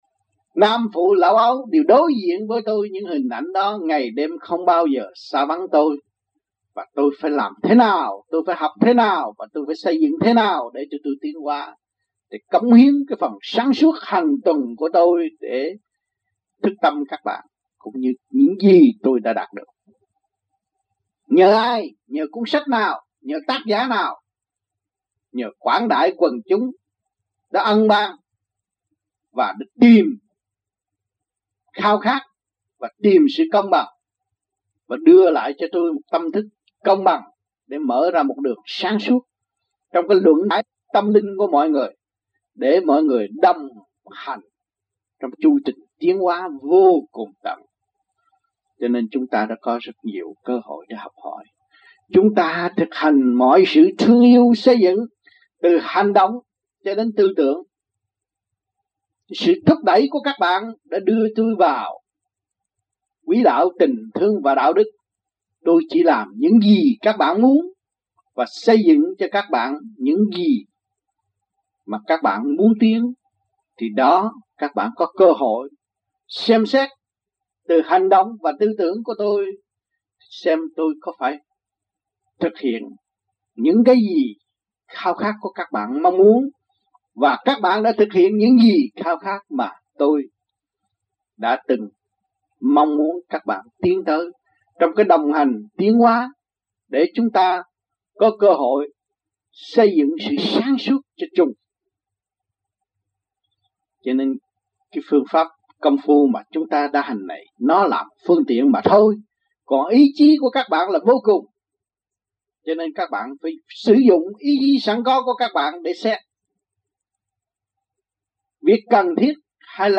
Địa danh : Montreal, United States Trong dịp : Sinh hoạt thiền đường
Phần Bài Giảng